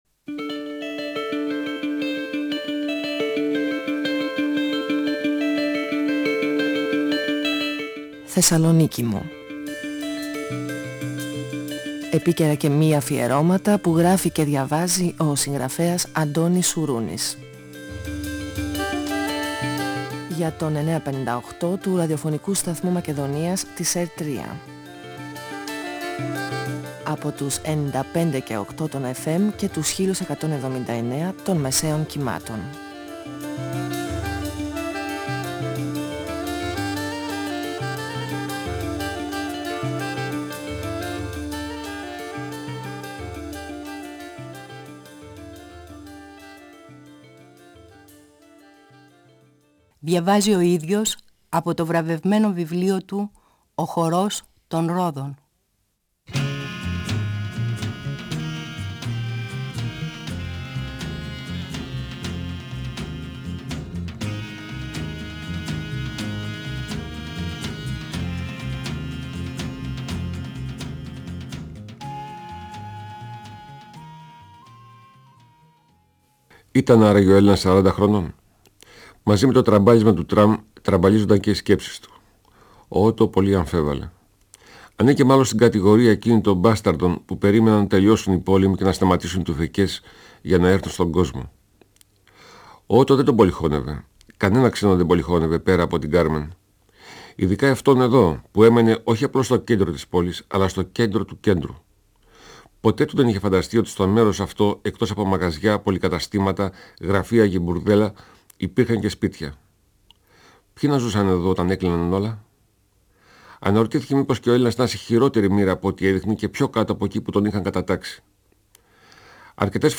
Ο συγγραφέας Αντώνης Σουρούνης (1942-2016) διαβάζει το πρώτο κεφάλαιο από το βιβλίο του «Ο χορός των ρόδων», εκδ. Καστανιώτη, 1994. Ο Ότο, παίκτης του καζίνου, που πολέμησε στην Κρήτη (1941) και θαυμάζει τους Κρητικούς, περιγράφει τον Nούση.